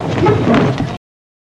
Chair Slide And Thump